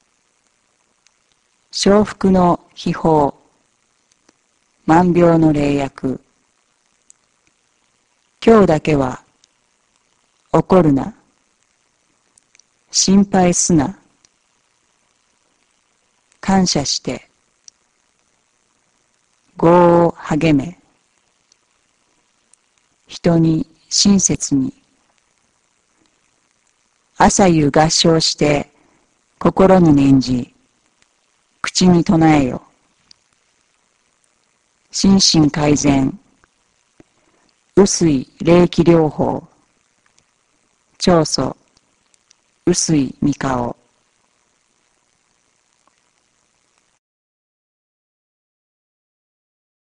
Ascolta i cinque principi